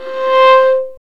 Index of /90_sSampleCDs/Roland L-CD702/VOL-1/STR_Viola Solo/STR_Vla Harmonx